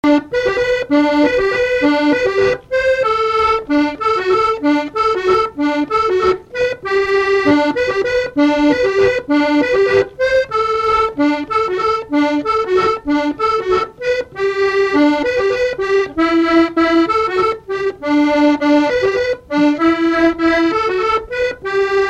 Chants brefs - A danser
danse : mazurka
Pièce musicale inédite